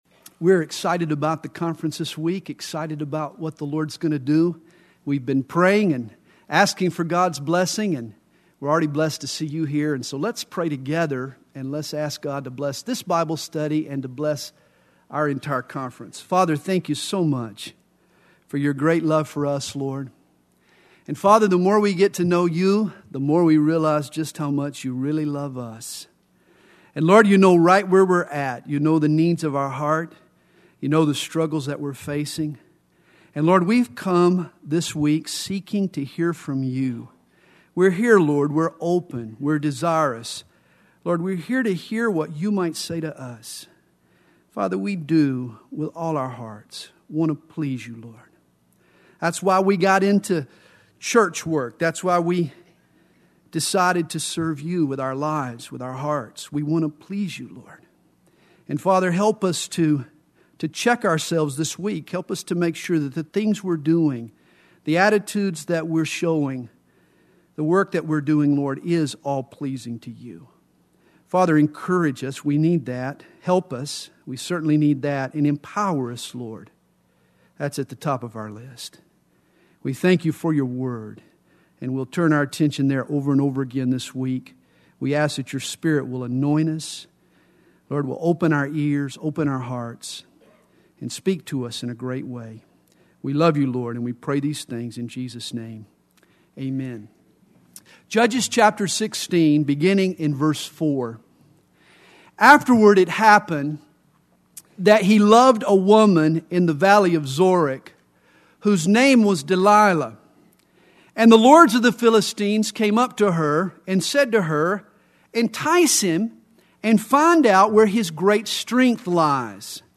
2005 DSPC Conference: Pastors & Leaders Date